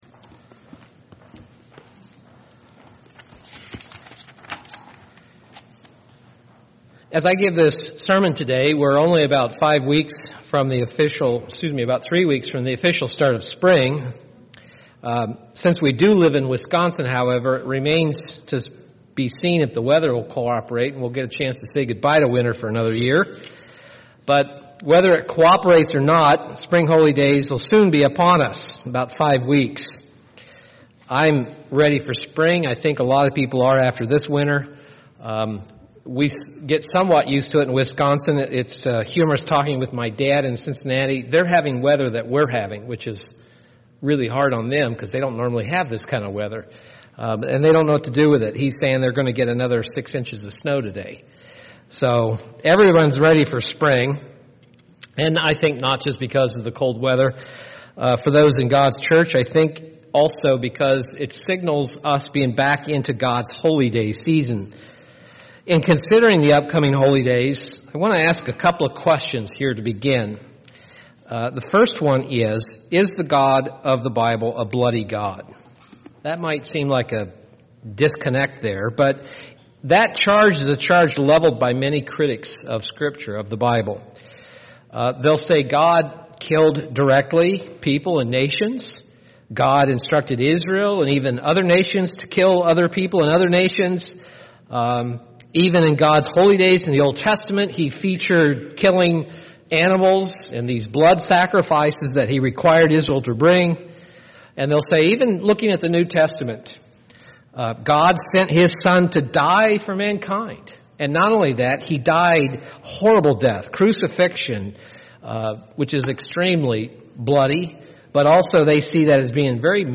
In today's sermon, we are going to review the 11th Fundemental Belief of the United Church of God.
Given in Milwaukee, WI